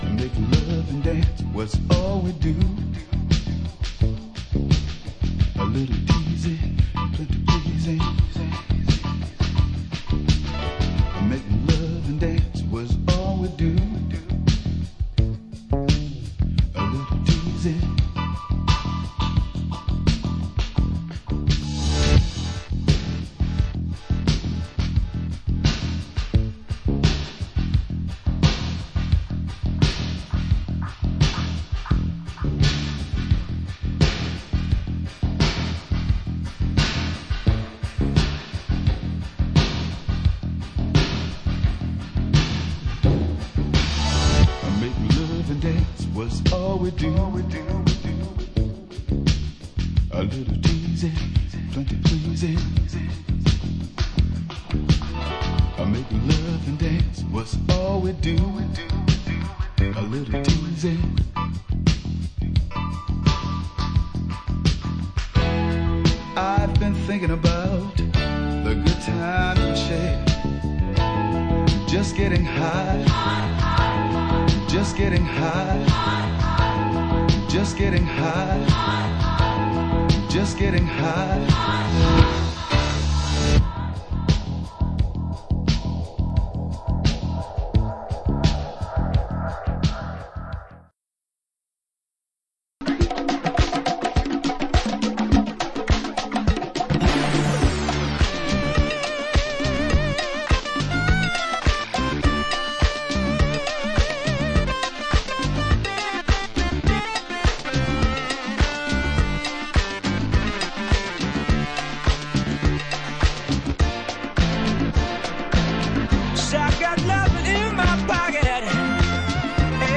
Format: 12"